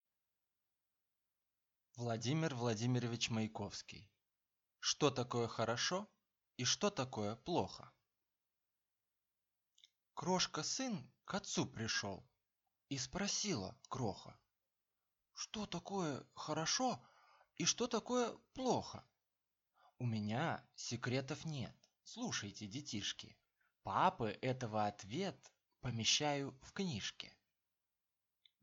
Аудиокнига Что такое хорошо и что такое плохо?